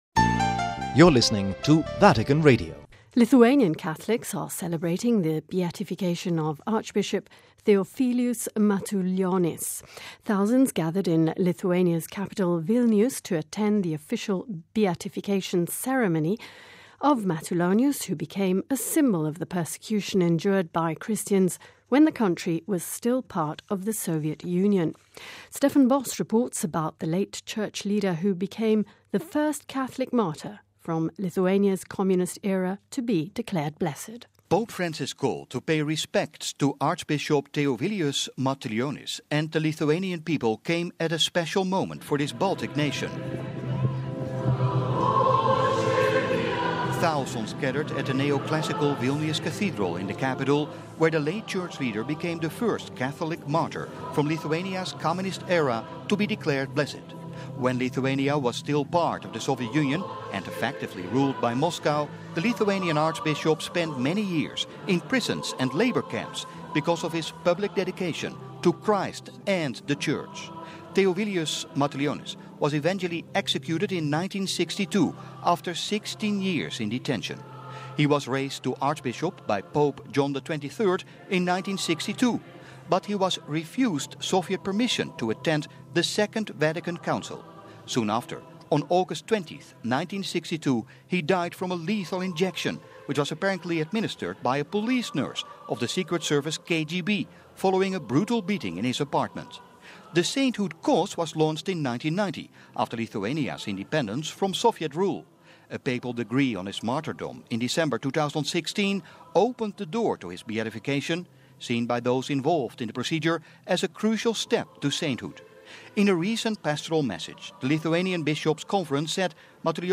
In front of the neo-classical Vilnius Cathedral in the capital, many sang and prayed as they remembered the suffering of a man who spent many years in prisons and labor camps because of his public dedication to Christ and the Church when Lithuania and effectively ruled by atheist leaders in Moscow.